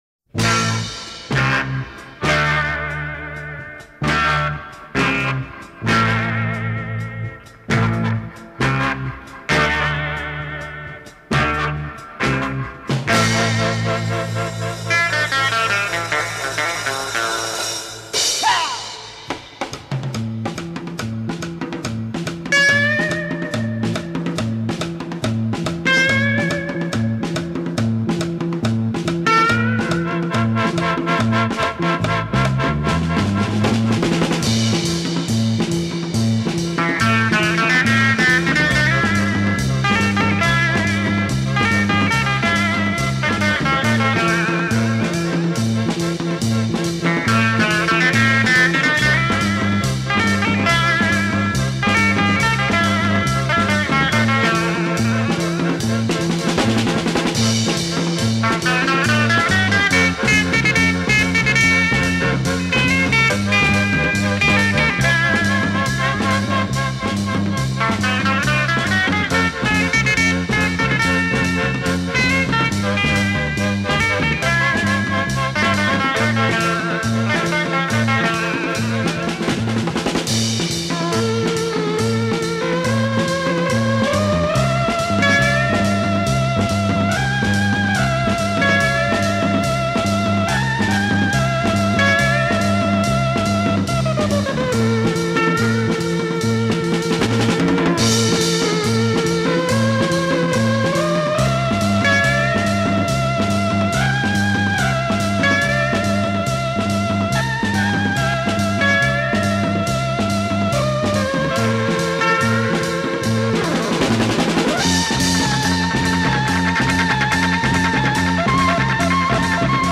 Peruvian folk song
based on traditional Andean music